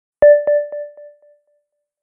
STARTUP_SOUND_SEMC.mp3